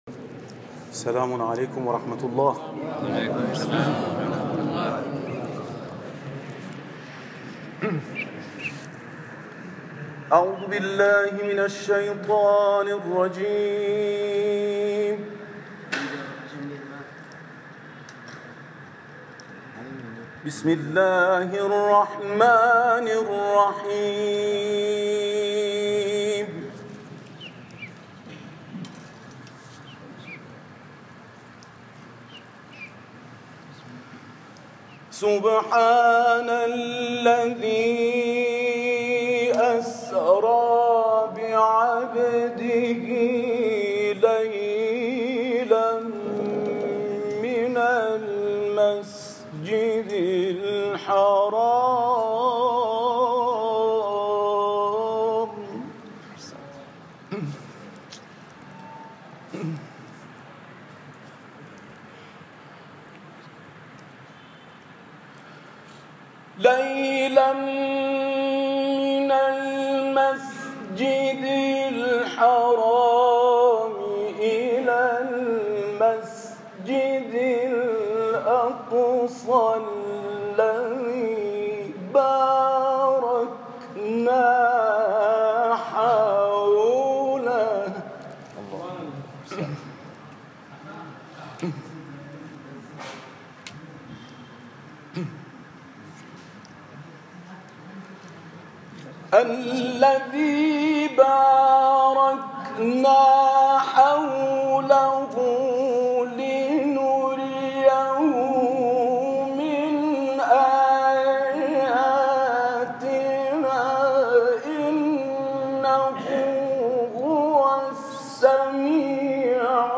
تلاوت
در مراسم روز قدس